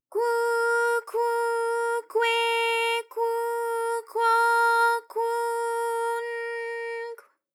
ALYS-DB-001-JPN - First Japanese UTAU vocal library of ALYS.
kwu_kwu_kwe_kwu_kwo_kwu_n_kw.wav